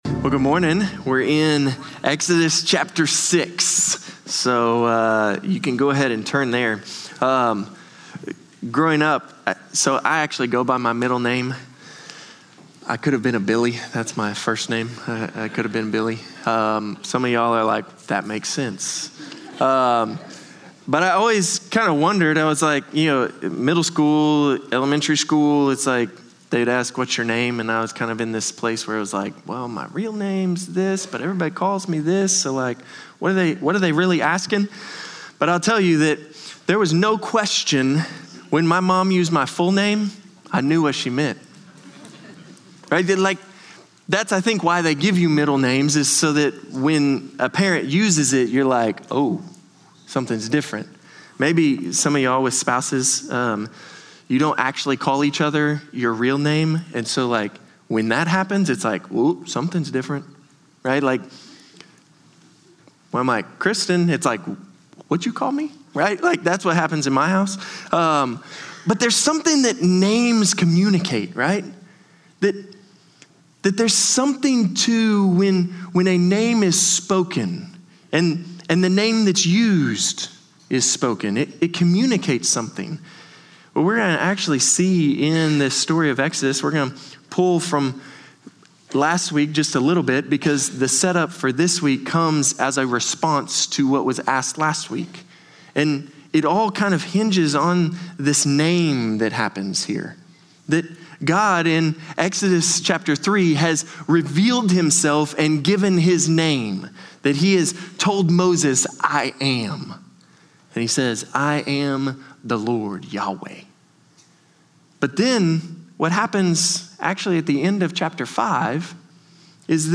Norris Ferry Sermons Mar. 8, 2026 -- Exodus 5:22-6:9 Mar 08 2026 | 00:33:16 1x 00:00 / 00:33:16 Subscribe Share Spotify RSS Feed Share Link Embed